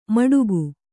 ♪ maḍugu